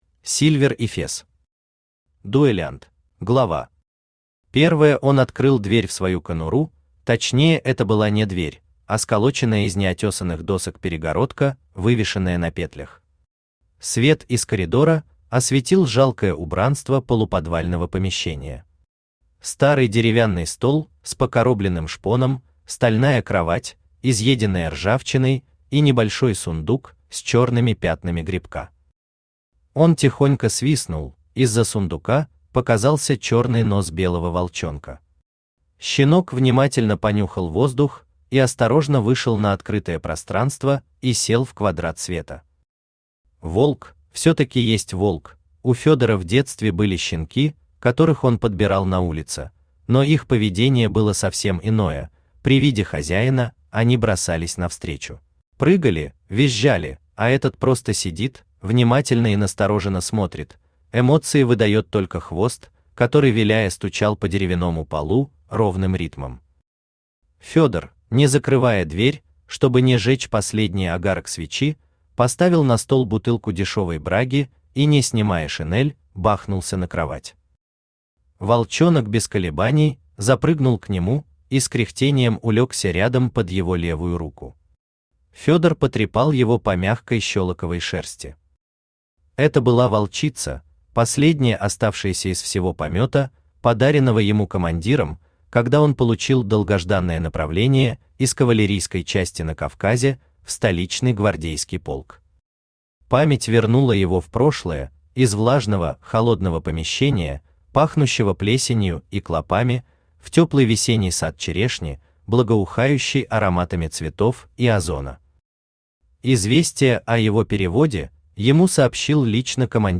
Аудиокнига Дуэлянт | Библиотека аудиокниг
Aудиокнига Дуэлянт Автор Сильвер Эфес Читает аудиокнигу Авточтец ЛитРес.